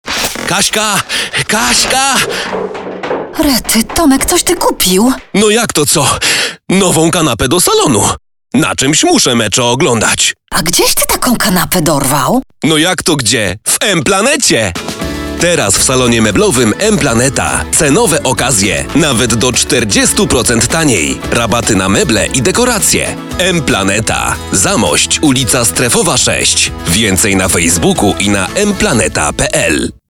Mężczyzna 30-50 lat
Mocny i dynamiczny tembr głosu z pewnością nada charakter Twojemu nagraniu.
Spot reklamowy